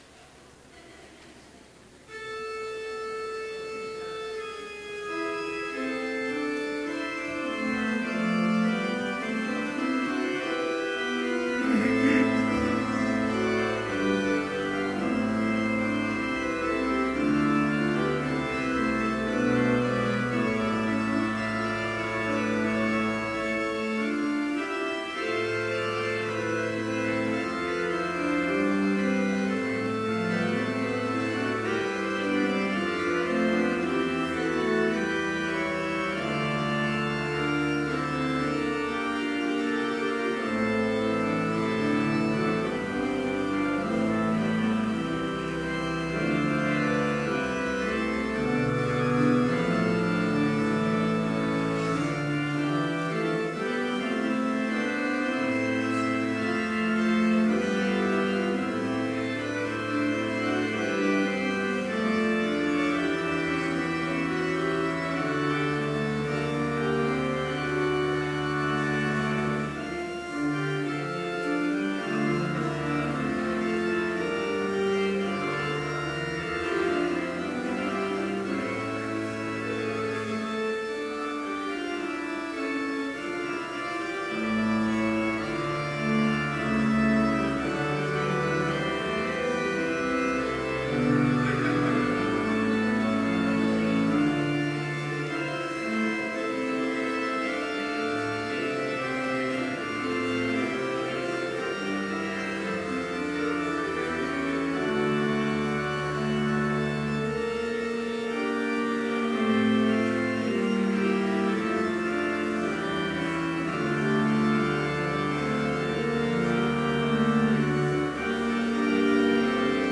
Complete service audio for Chapel - April 10, 2018